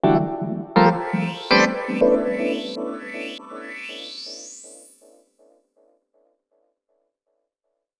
I have made something in the same spirit, but waaaay more low-fi.